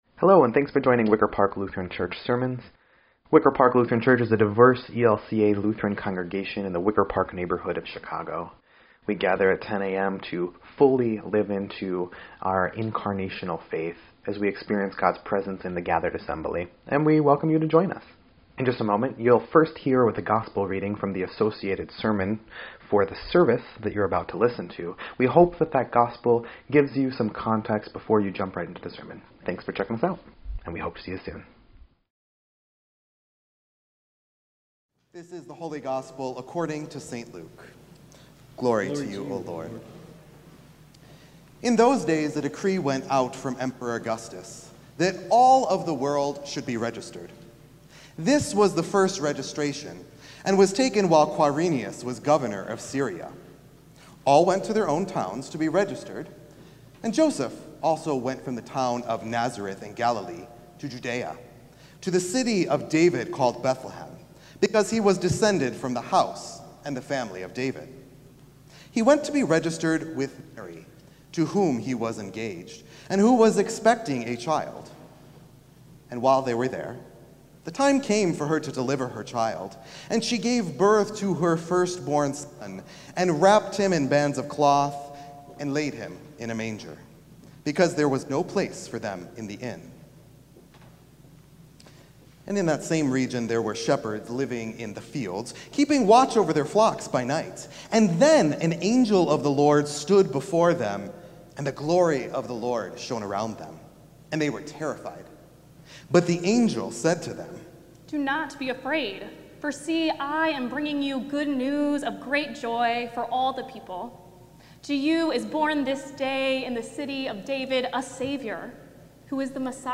Christmas Eve Service